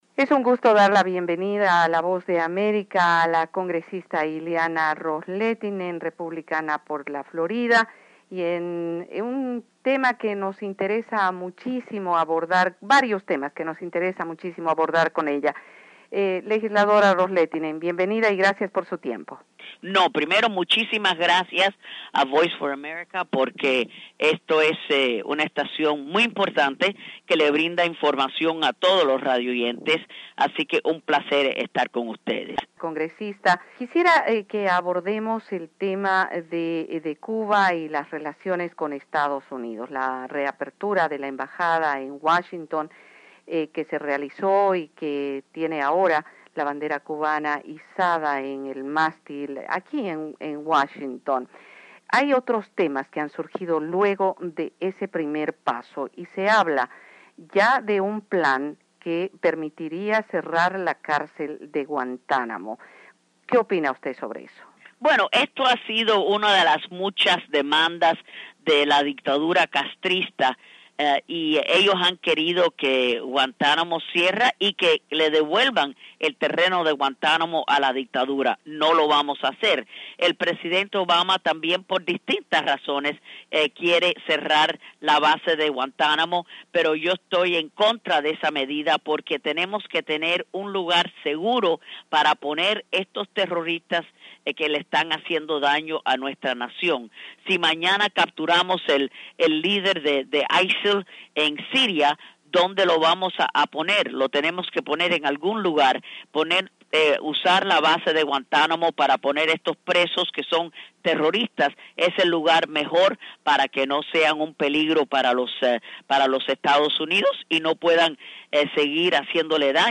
Entrevista con la legisladora republicana por Florida, Ileana Ros-Lehtinen